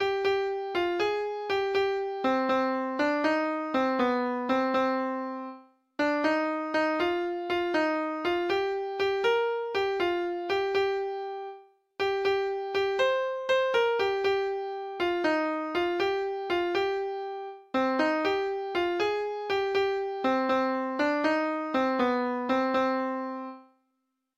Norsk folketone
Lytt til data-generert lydfil